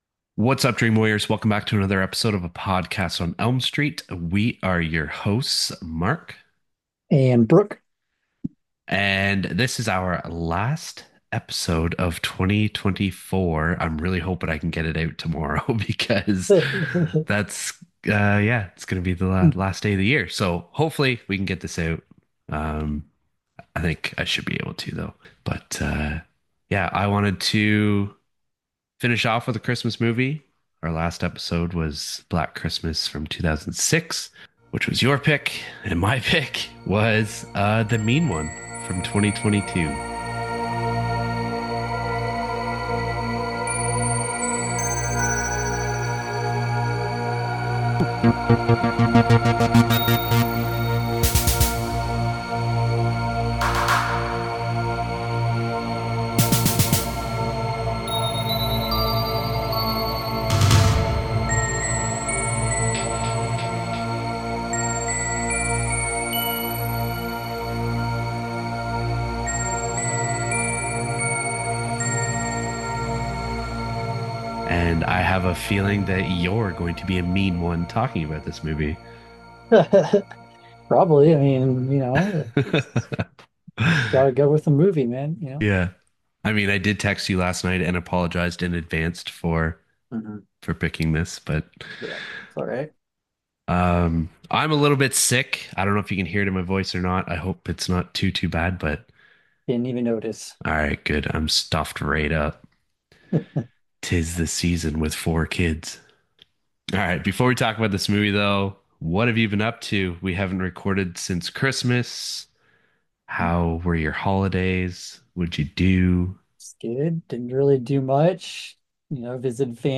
A podcast discussing horror movies from 2 Canadian horror movie fans. Every week we get together and discuss a different movie in length.